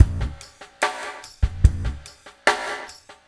BOL GASP  -R.wav